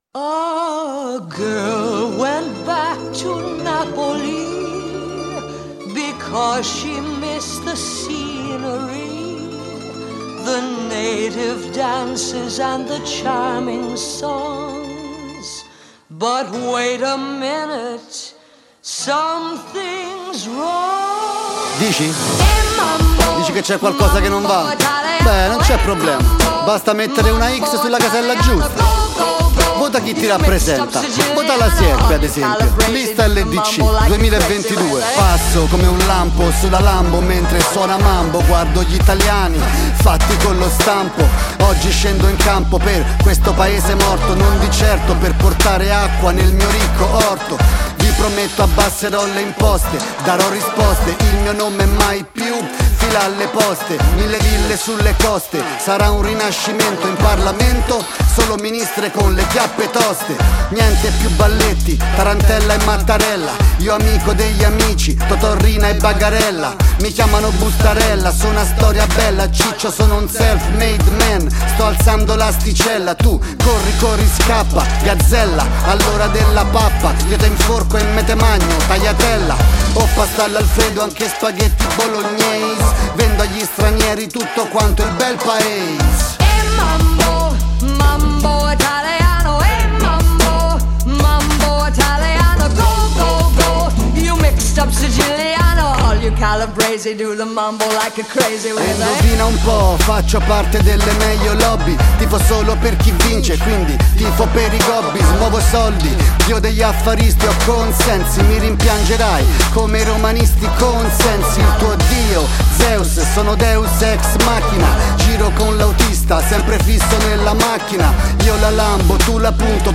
campionamento volutamente spudorato
Registrato presso lo studio di Anzio